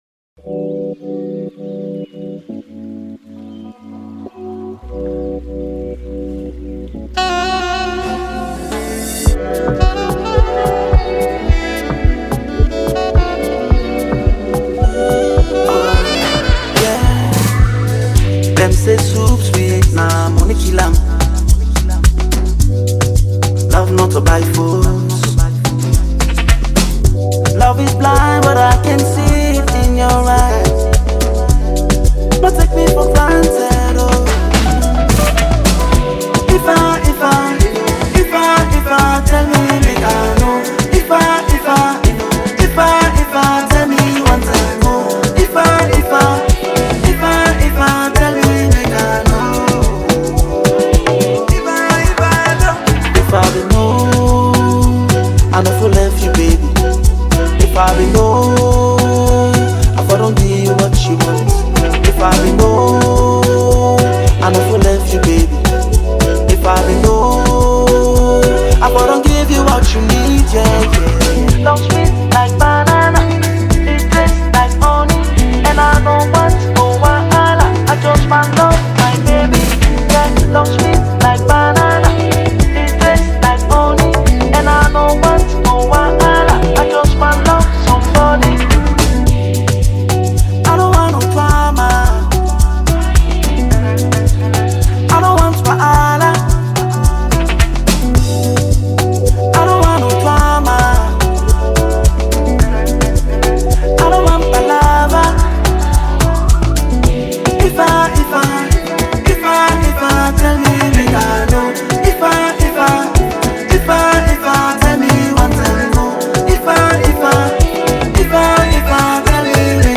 Afro-pop and R&B